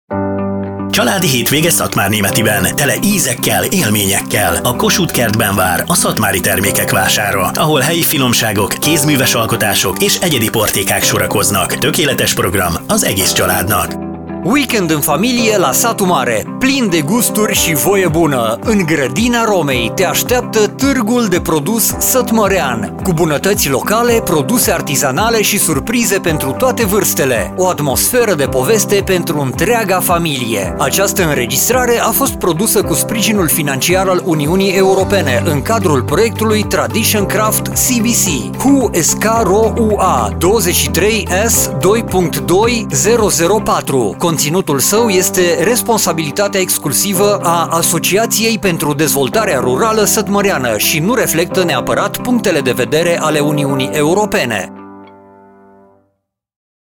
Radio spot - Târg de "Weekend in Familie" - "Családi Hétvége" Termékvásár